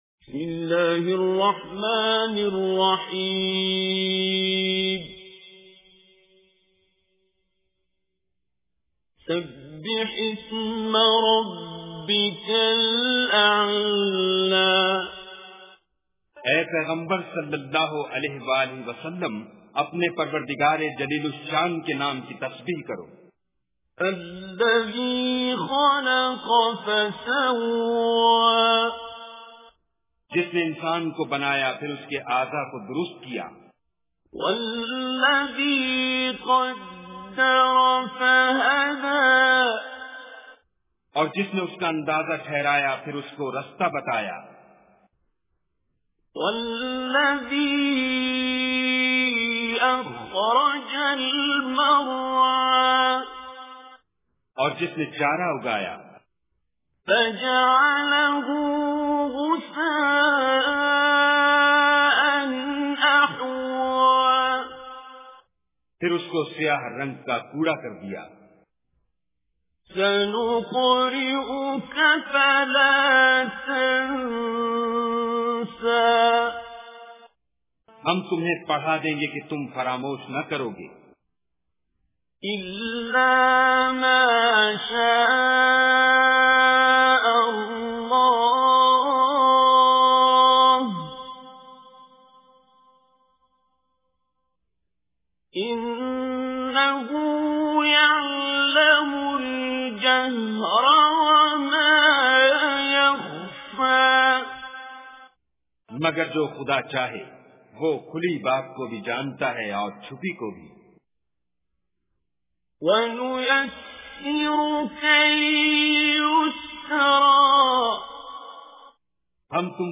Surah Al Ala Recitation with Urdu Translation
Surah Ala is 87th chapter of Holy Quran. Listen online and download mp3 tilawat / recitation of Surah Ala in the voice of Qari Abdul Basit As Samad.